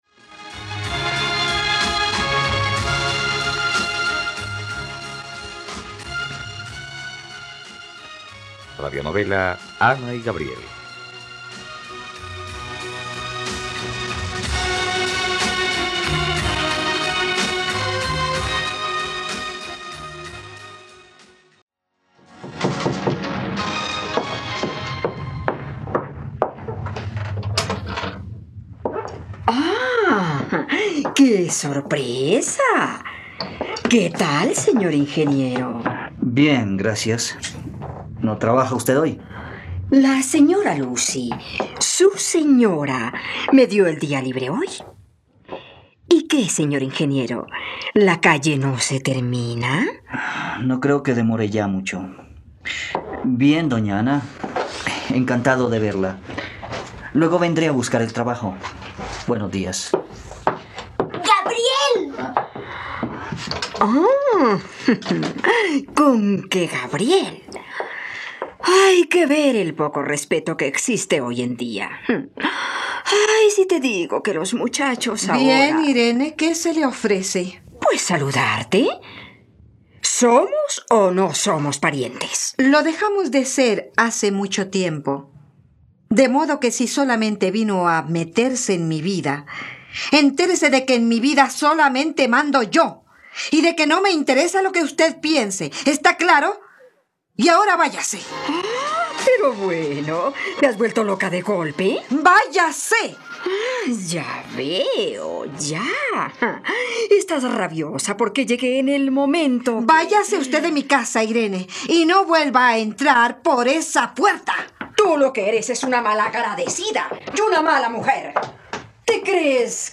Ana y Gabriel - Radionovela, capítulo 40 | RTVCPlay